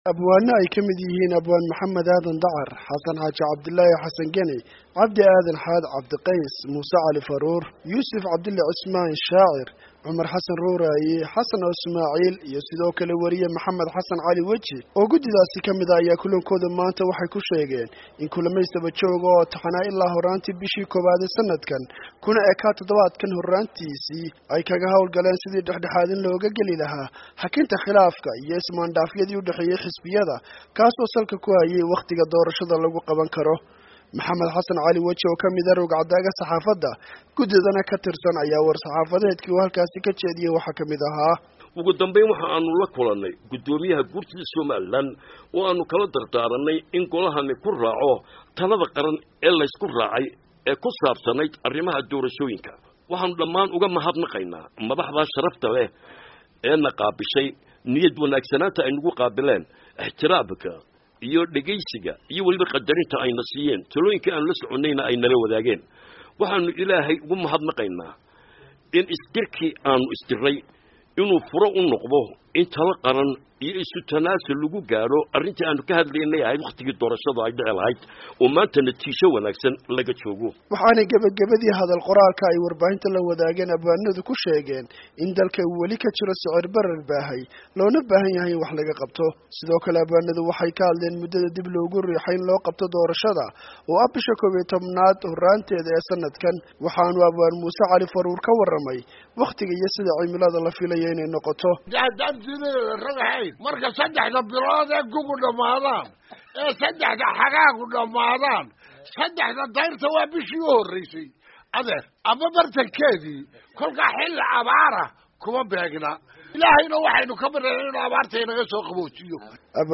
Warbixin